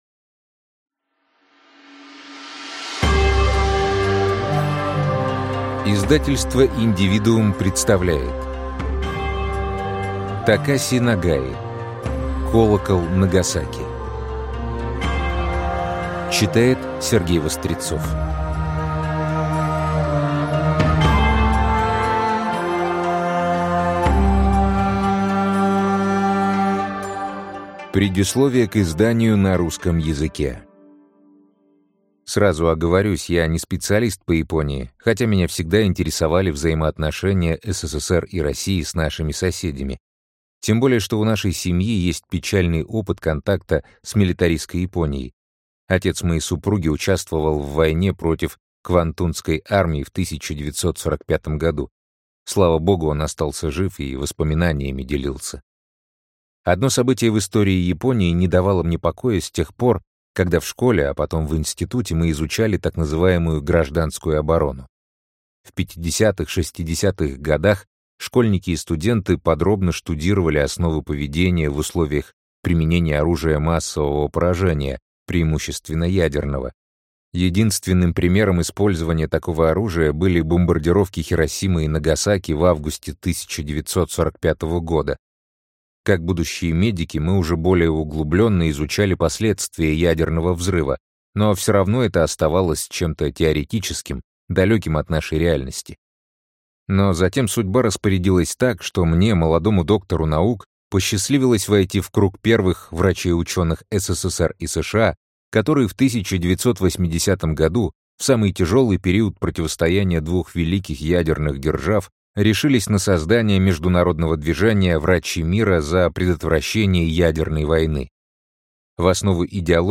Аудиокнига Колокол Нагасаки | Библиотека аудиокниг